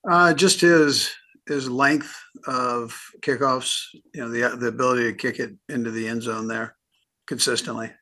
Andy Reid press conference